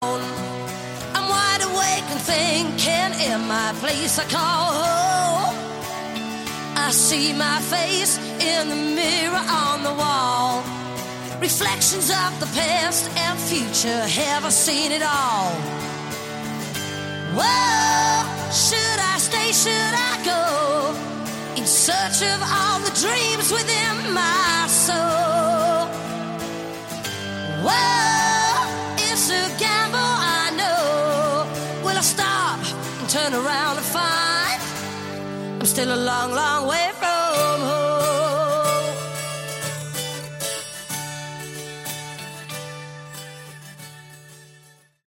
Category: Hard Rock
electric and acoustic guitars, keyboards, mandolin
lead vocals
bass, keyboards
lead and rhythm guitars
drums, percussion